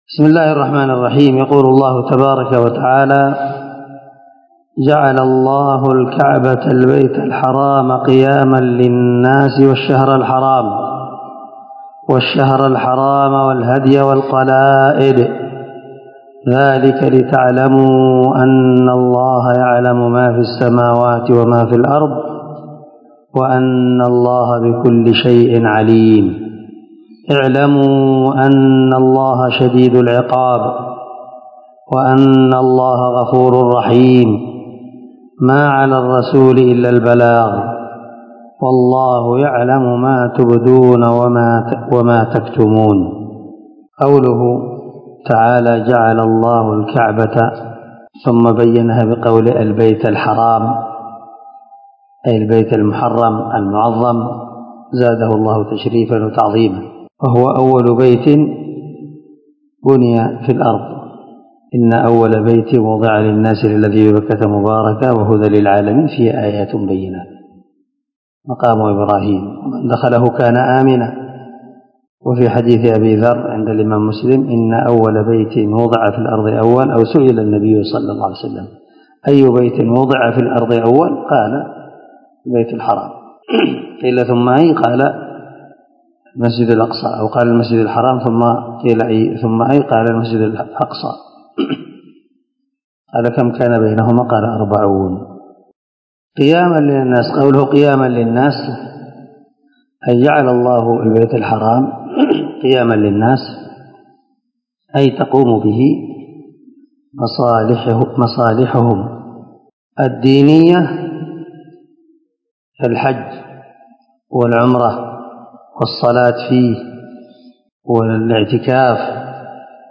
386الدرس52 تفسير آية ( 97 - 99 ) من سورة المائدة من تفسير القران الكريم مع قراءة لتفسير السعدي